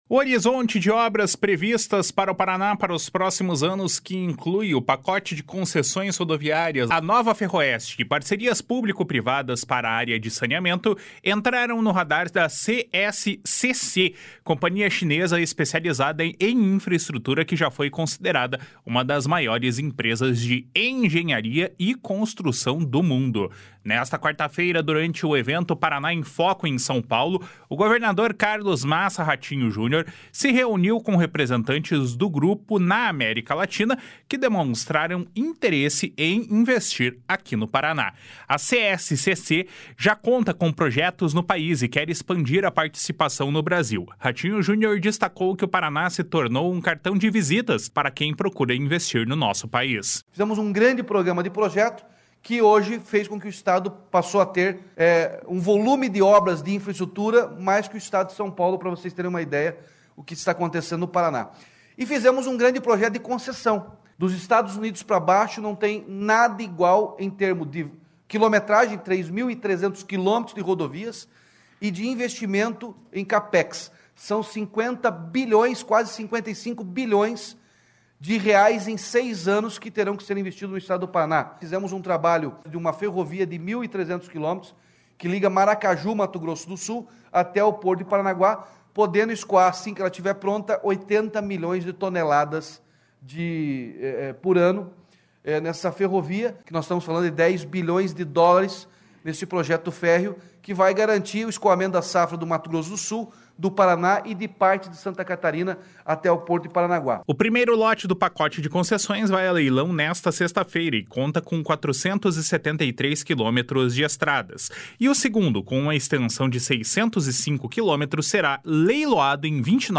Ratinho Junior destacou que o Paraná se tornou um cartão de visitas para quem procura investir no Brasil. // SONORA RATINHO JUNIOR //